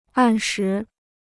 按时 (àn shí): on time; before deadline.